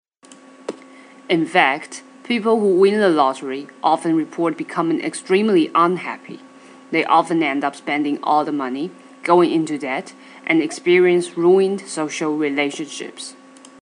台湾籍旅美老师今天交给我们的句子是：